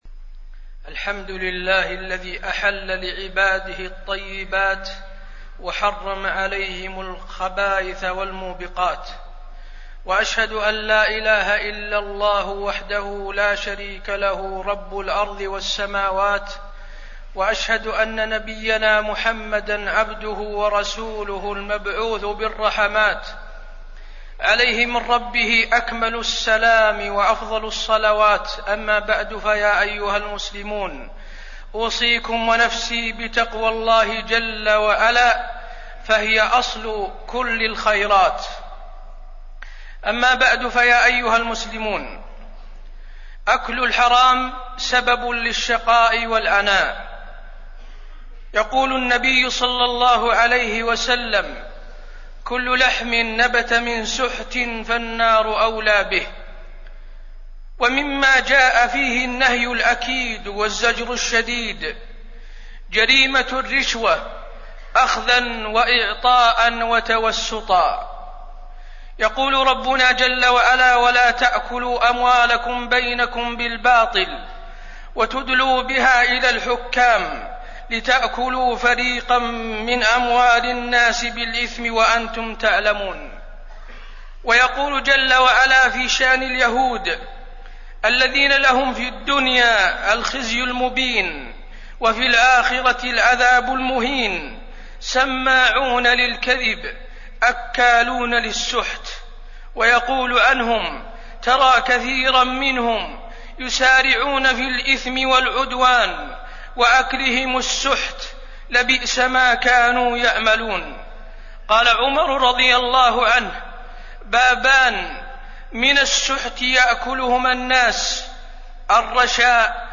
تاريخ النشر ١٨ محرم ١٤٣٢ هـ المكان: المسجد النبوي الشيخ: فضيلة الشيخ د. حسين بن عبدالعزيز آل الشيخ فضيلة الشيخ د. حسين بن عبدالعزيز آل الشيخ خطورة الرشوة